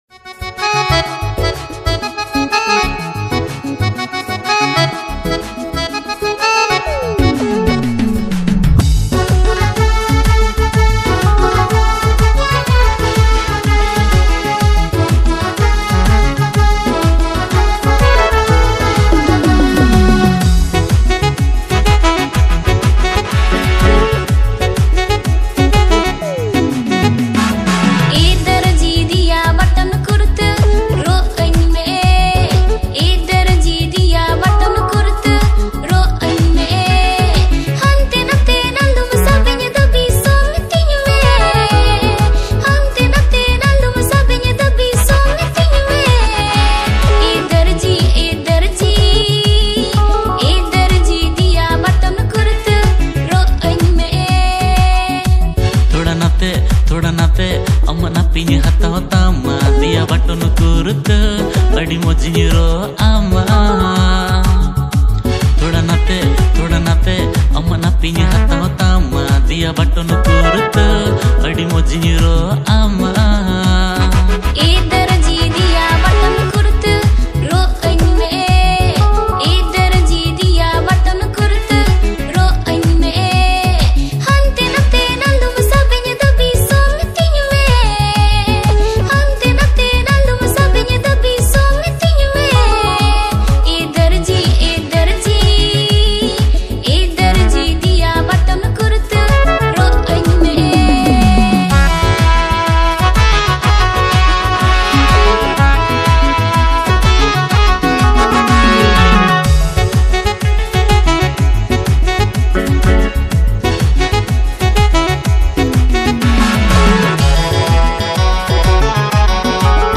• Male Artist